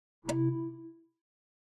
Системные звуки Apple iMac и MacBook Pro и Air в mp3 формате
imac-konec-kopirovaniia.mp3